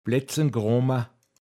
pinzgauer mundart
Bleznkråma, m. Mensch mit viel Schorf im Gesicht